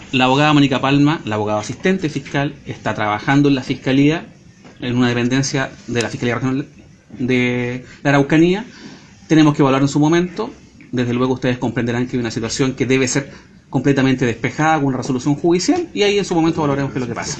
Declaraciones del fiscal Paredes, tras reunirse por segunda vez, en menos de una semana, con el intendente de La Araucanía, Luis Mayol, cita a la que se sumó el jefe de la Novena Zona Policial de Carabineros, el general Andrés Gallegos.